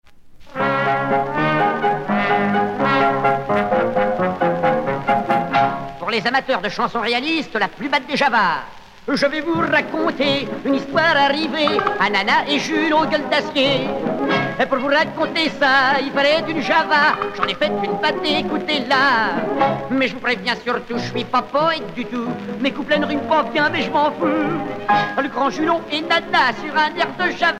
danse : java
Pièce musicale éditée